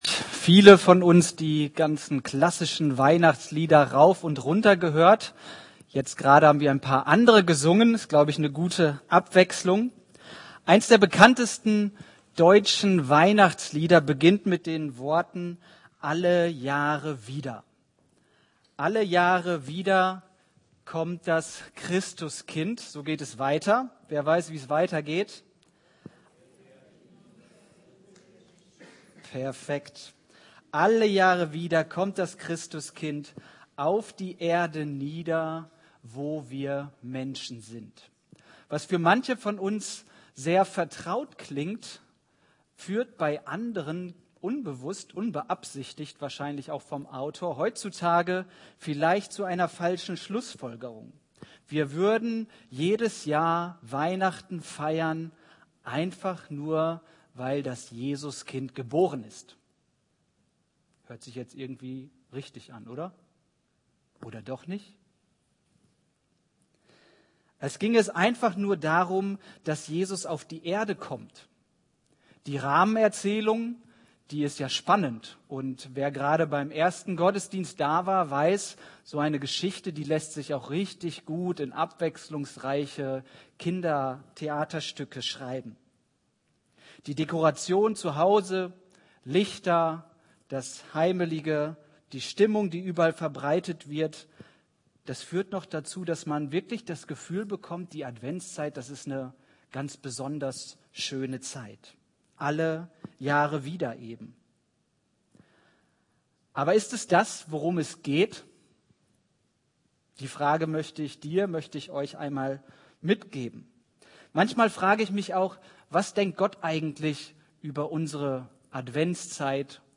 Predigt Briefe
Predigt vom Heiligabend 2025